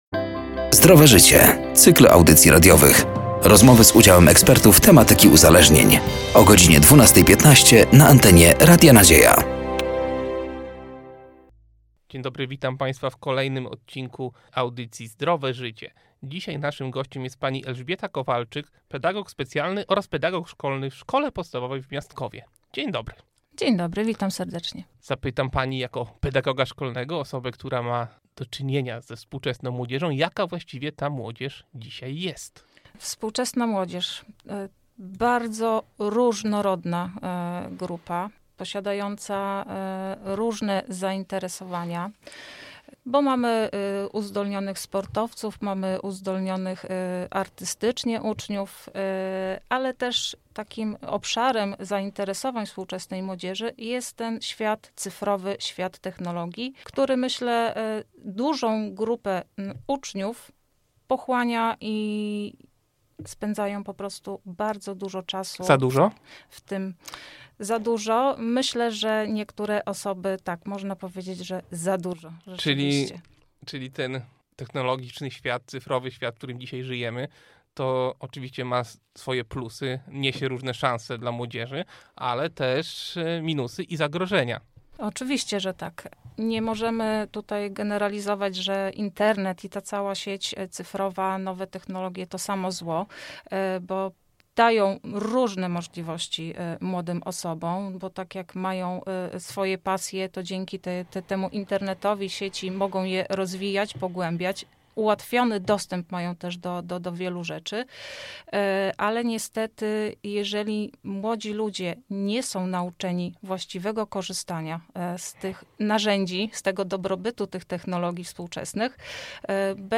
„Zdrowe Życie” to cykl audycji radiowych. Rozmowy z udziałem ekspertów tematyki uzależnień.